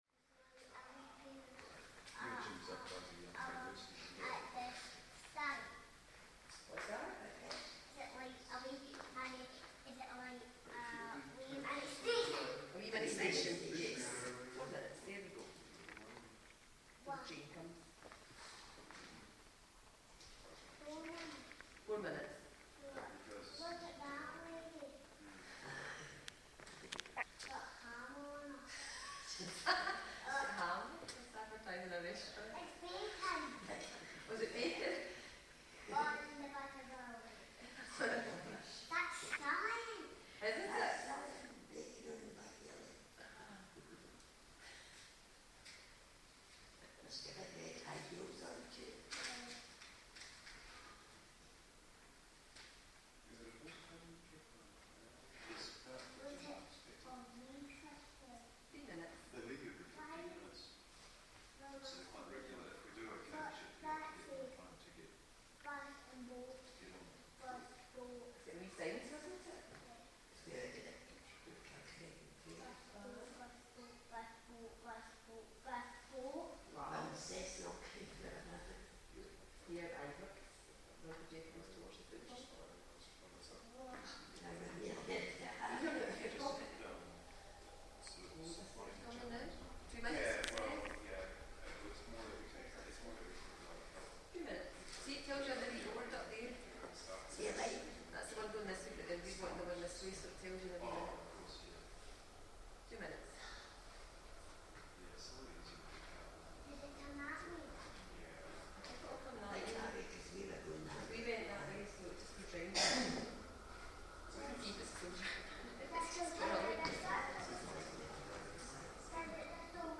Cessnock subway station, Glasgow uksm